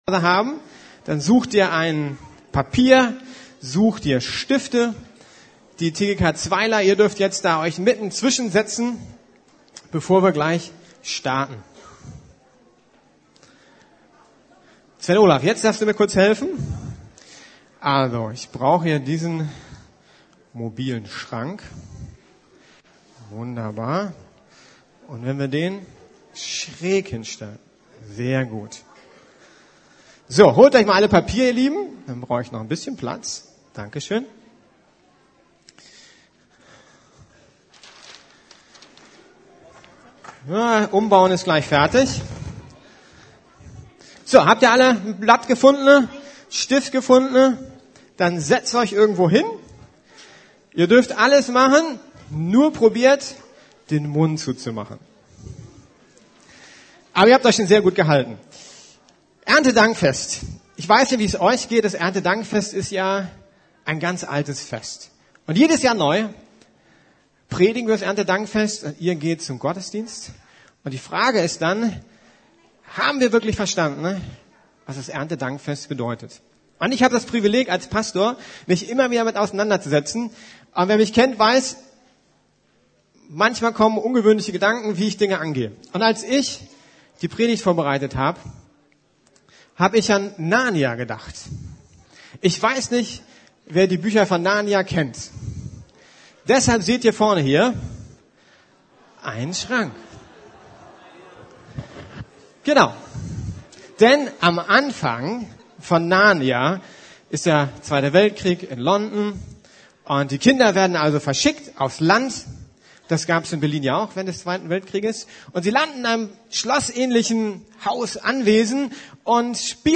Loben, danken, teilen! ~ Predigten der LUKAS GEMEINDE Podcast